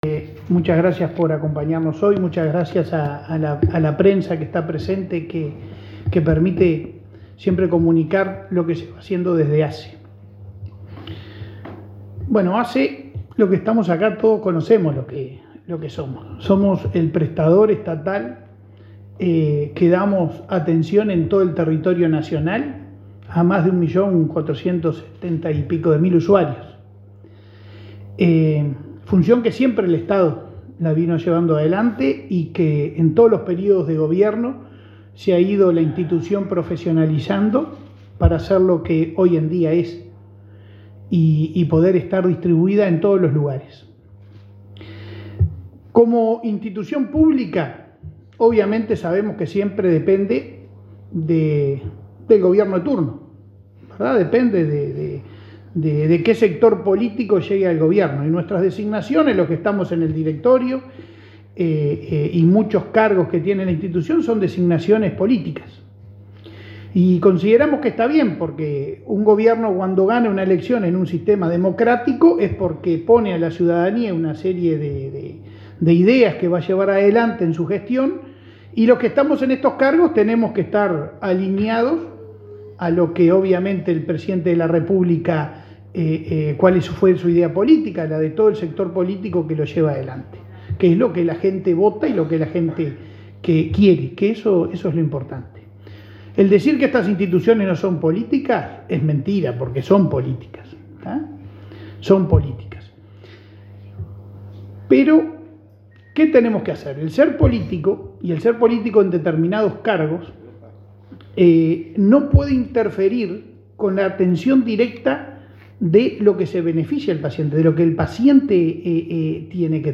Palabras del presidente de ASSE, Leonardo Cipriani